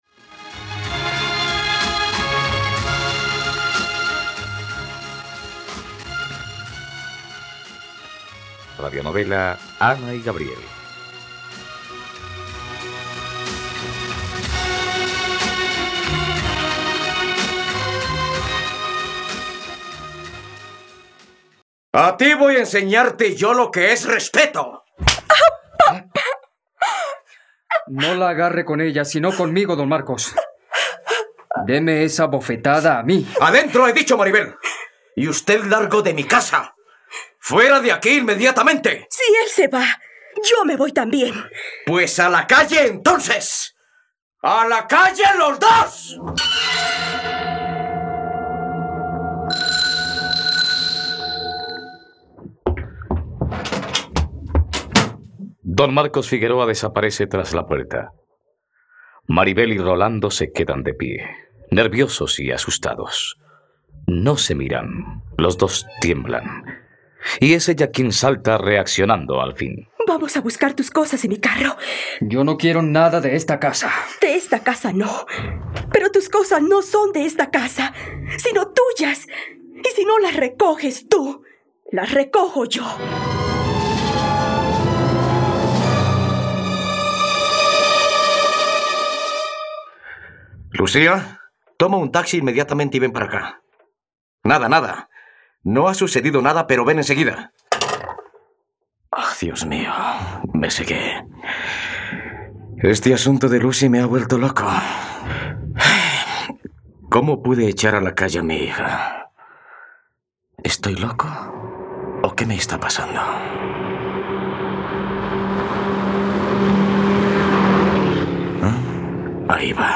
..Radionovela. Escucha ahora el capítulo 96 de la historia de amor de Ana y Gabriel en la plataforma de streaming de los colombianos: RTVCPlay.